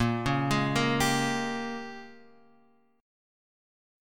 A# Minor 13th